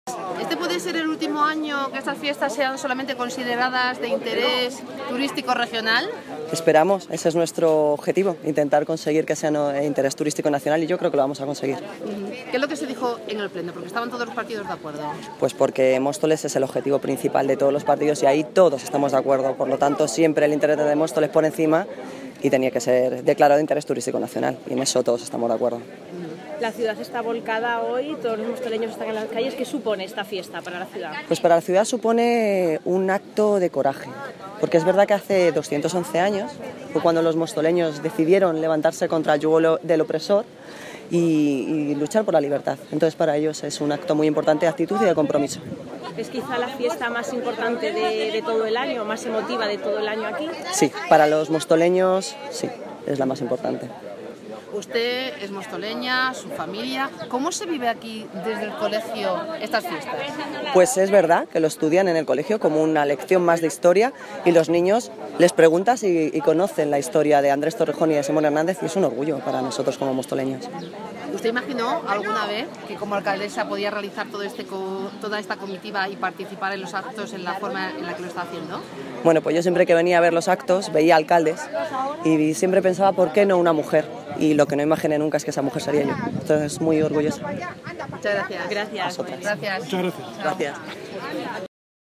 Alcaldesa de Móstoles (Noelia Posse) Sobre el día grande de homenaje a los Héroes de la Independencia